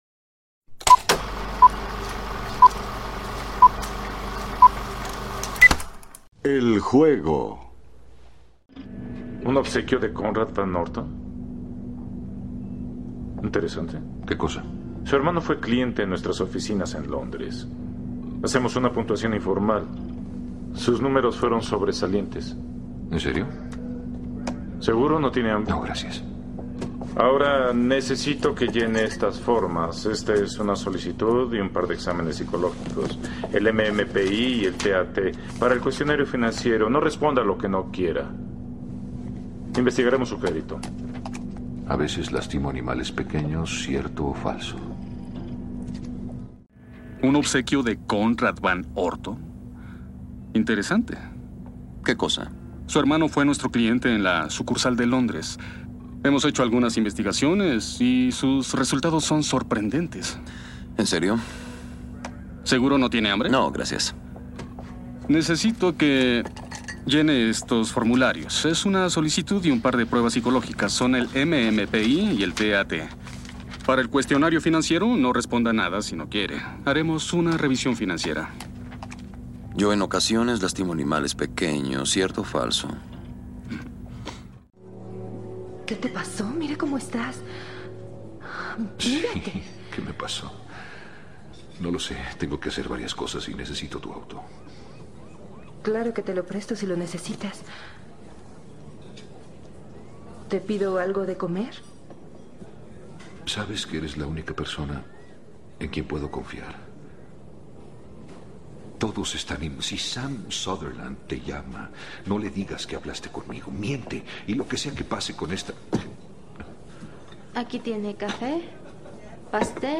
Doblaje latino (original y redoblaje)